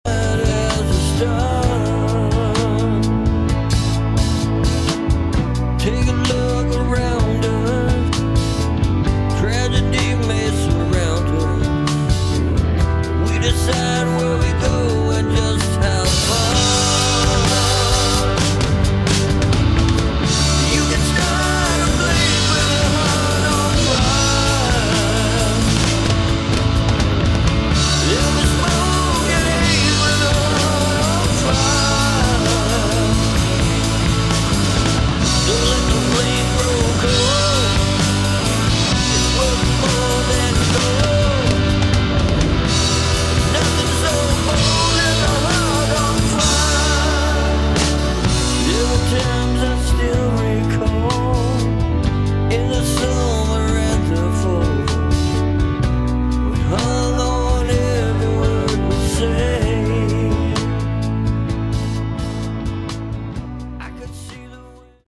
Category: Melodic Hard Rock
guitar, vocals, organ
guitar, bass, vocals
drums